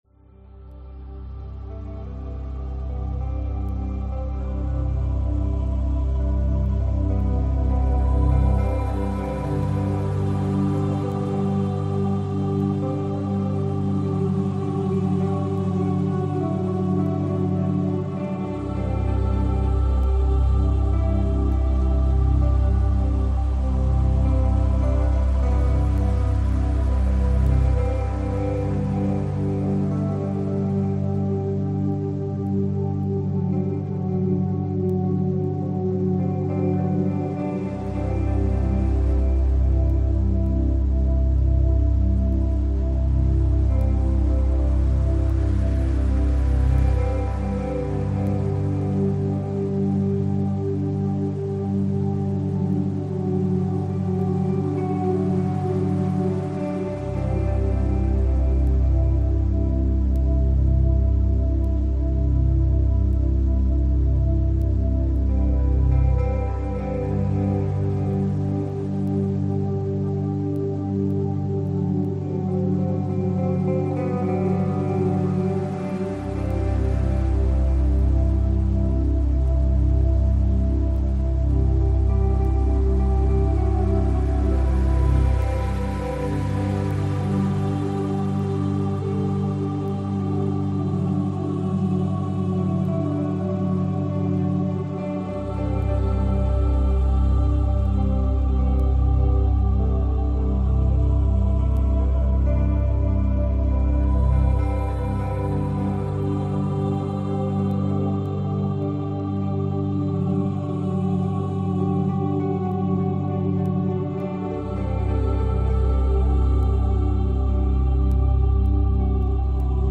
Легкая музыка для быстрого погружения в сон